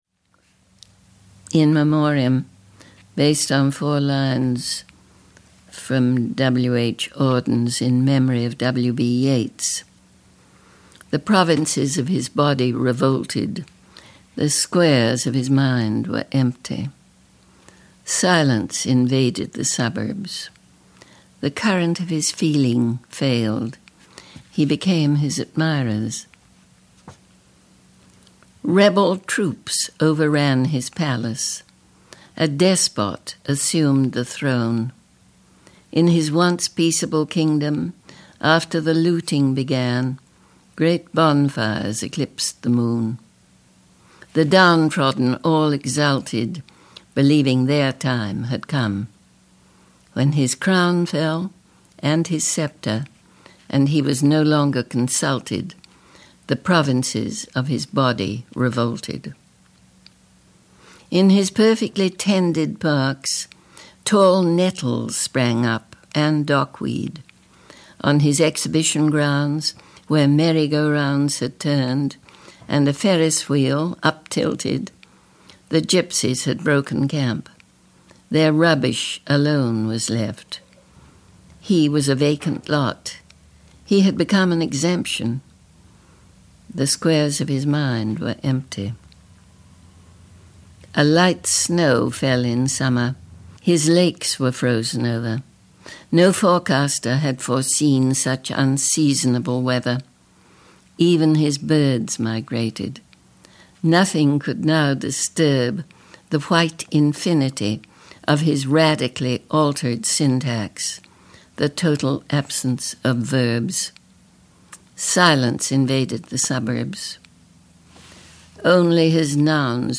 P.K. Page reads In Memoriam from Hologram: A Book of Glosas
This poem is from The Filled Pen: an Outlaw Editions Audio book. Poems by P.K. Page Written and narrated by P.K. Page.